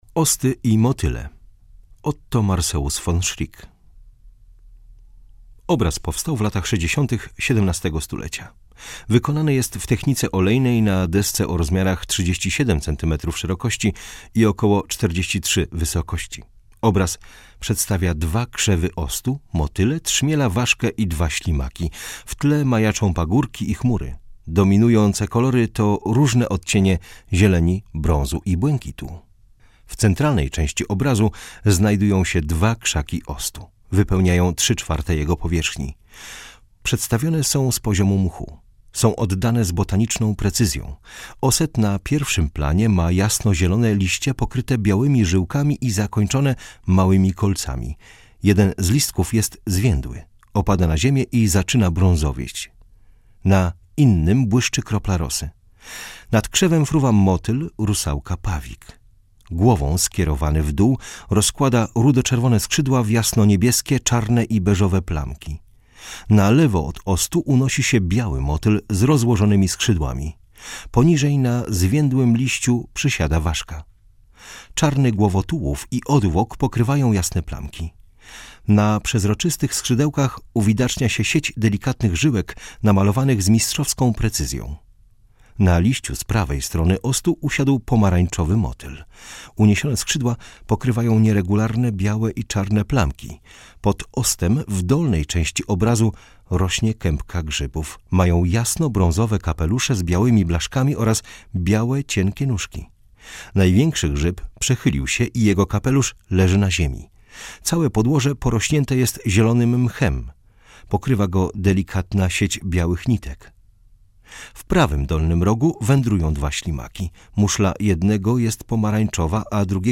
Audiodeskrypcja - EUROPEUM